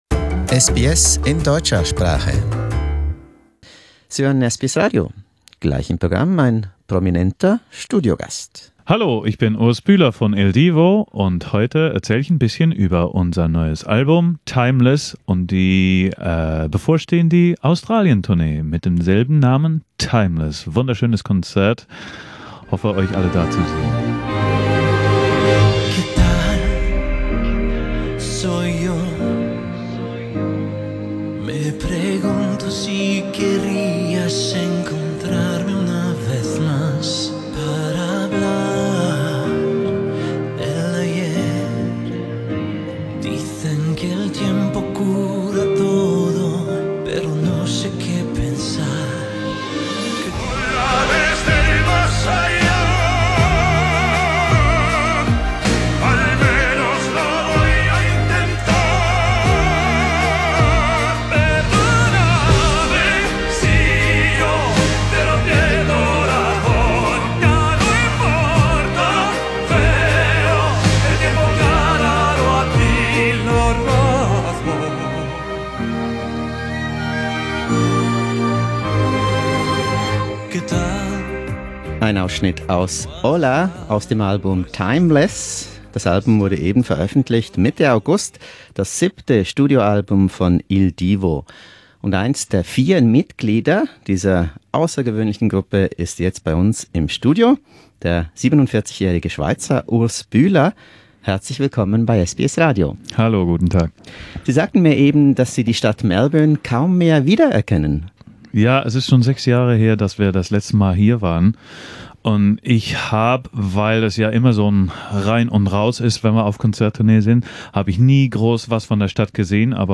Il Divo visits SBS Radio in Australia – A delightful studio interview with Swiss tenor Urs Bühler
Urs Bühler im SBS Studio am Federation Square in Melbourne